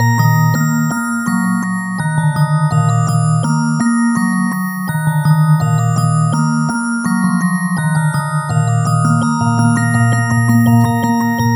Wind Chimes.wav